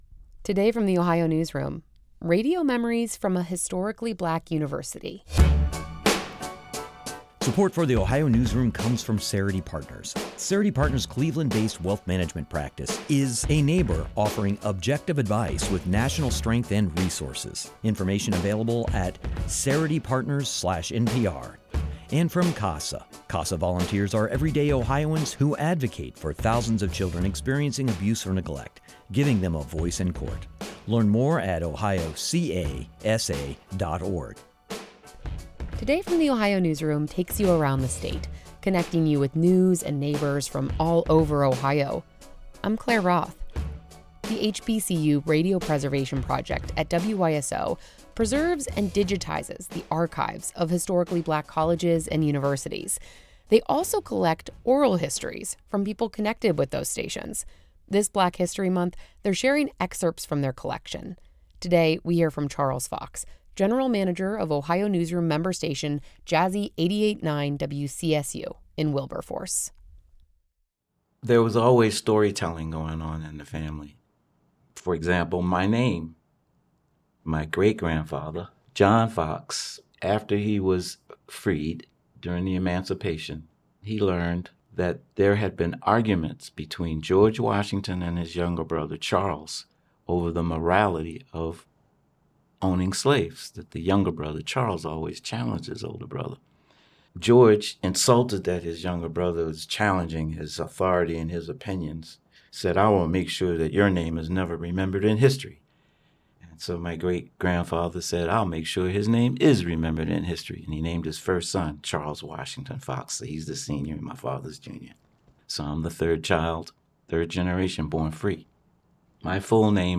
Interview Highlights: